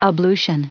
39_ablution.ogg